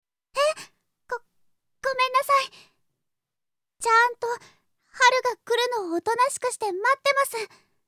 ・生まれつき病弱で、ほとんど外に出た事がない
【サンプルボイス】